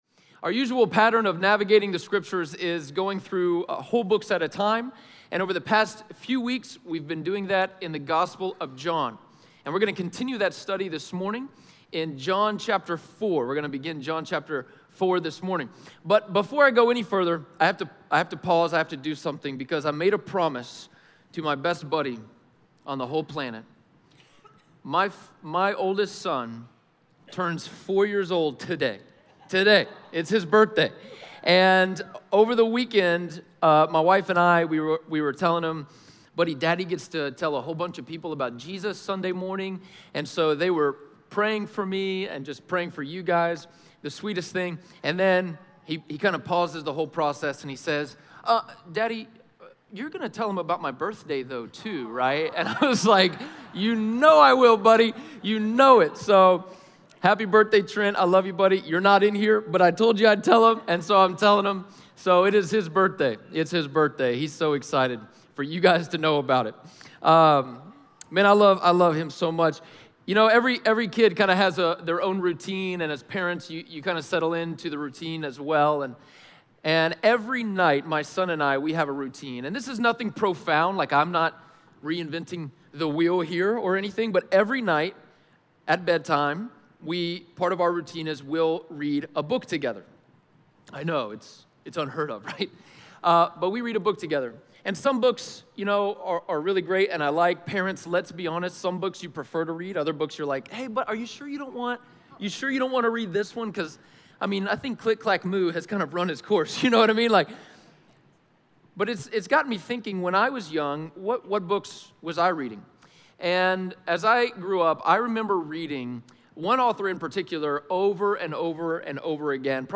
John 4:1-26 Audio Sermon Notes (PDF) Ask a Question Scripture: John 4:1-26 Something’s missing.